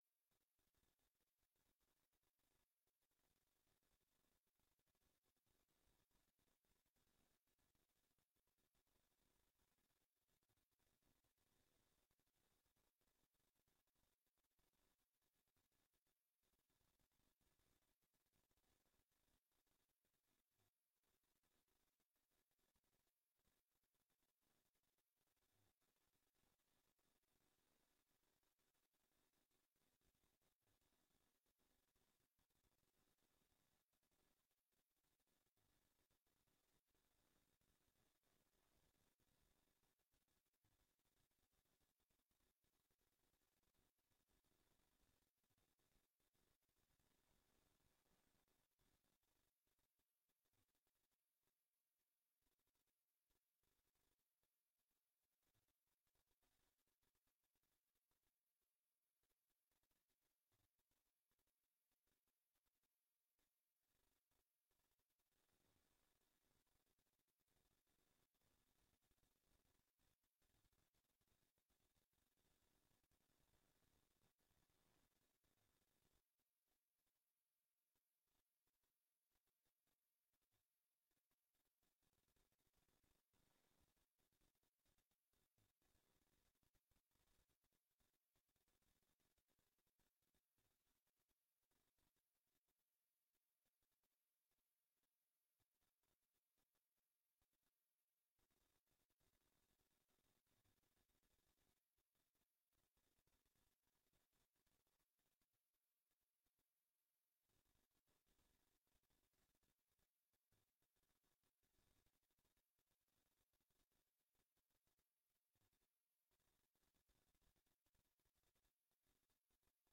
Extra bijzondere raadsvergadering Installatie van Roelien Kamminga als burgemeester van Groningen
Live uitgezonden Raadzaal